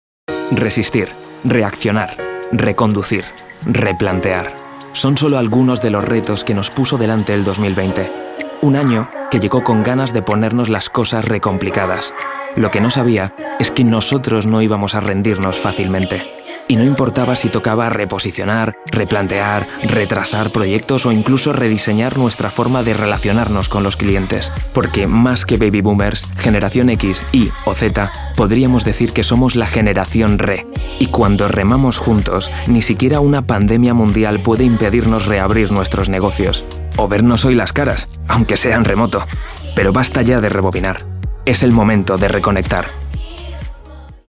Commercial, Natural, Urban, Cool, Warm
Explainer
He is characterized by having a special register that can lead to different timbres and tones, and having a voice with a fairly wide tonal range. From a very metallic, plastic, and high-pitched voice, to a much warmer and deeper voice, covering different styles and characters that he can interpret and imitate by having a good ear.